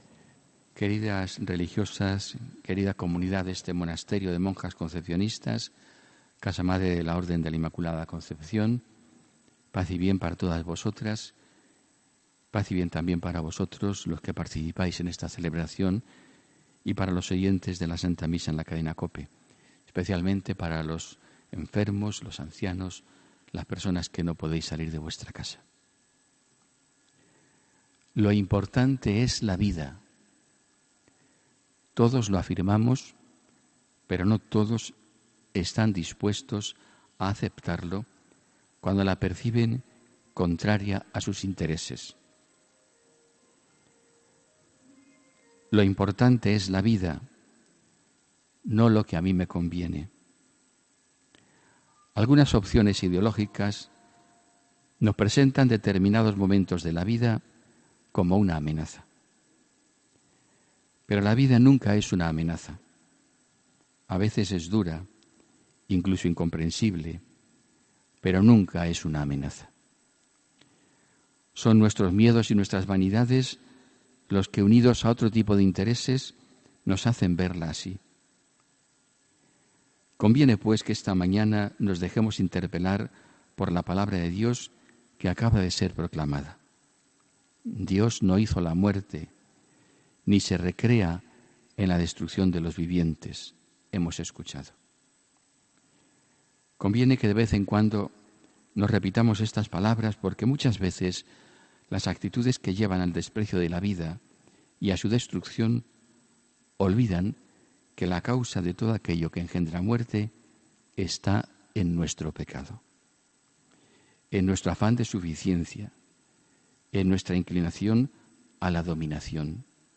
HOMILÍA 1 JULIO 2018